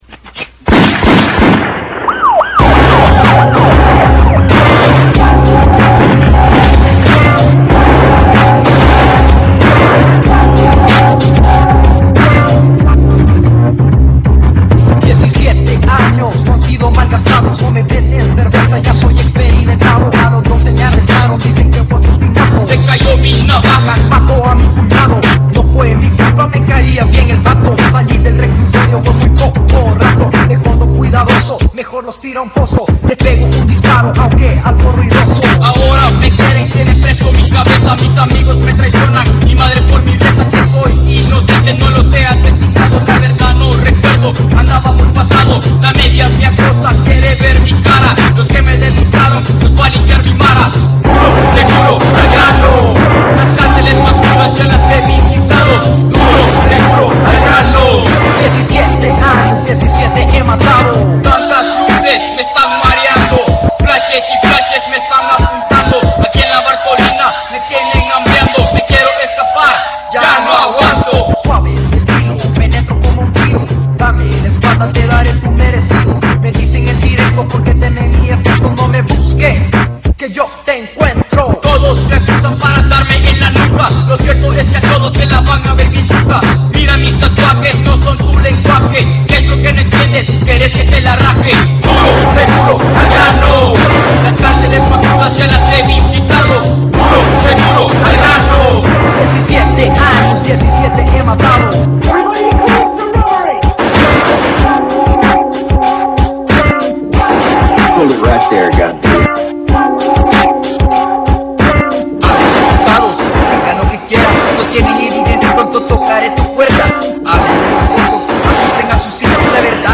los pioneros del RAP en EL SALVADOR.